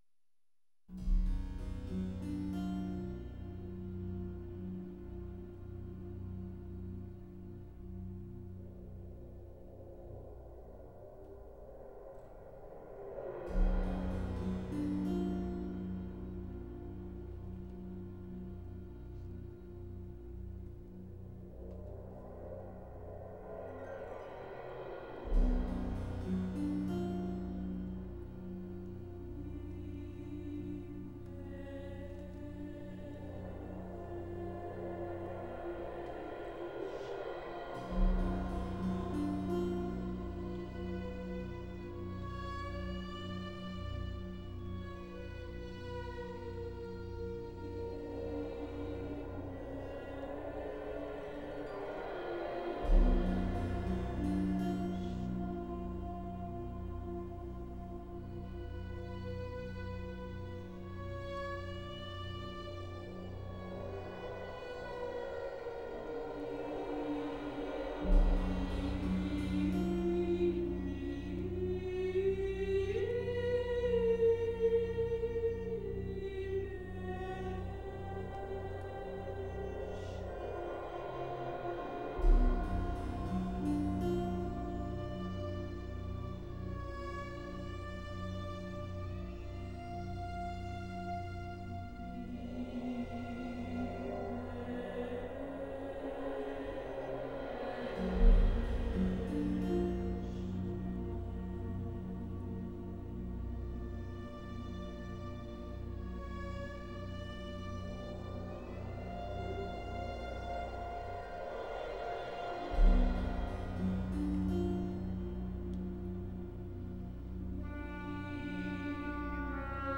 soprano
Recorded in 1992 at the Centennial Concert Hall in Winnipeg